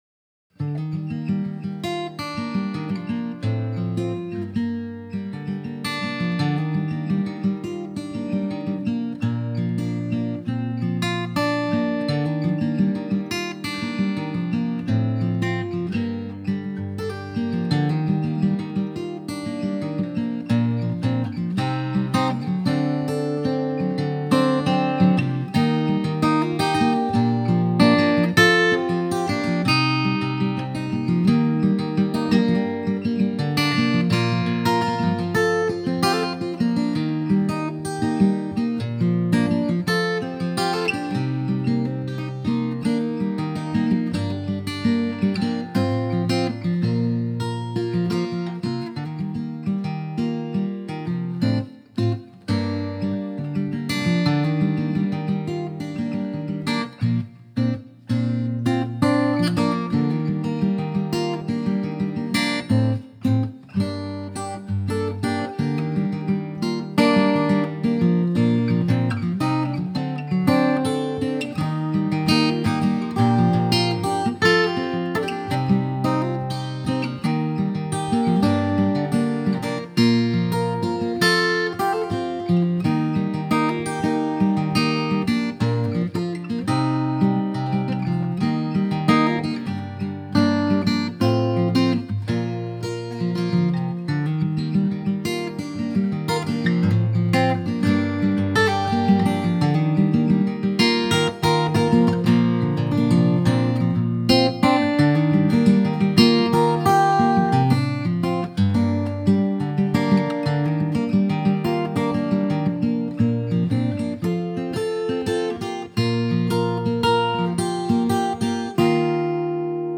Here is a short instrumental I recorded in March 2017, I don’t think I “released” this on to SoundCloud or BandCamp at the time. I have now EQ’d it (basically put in a low cut) and added a little splash of reverb and some stereo widening to make it sweeter and more open to listen to.
It’s called Cadd9, because that’s basically the chord on which the whole composition hinges.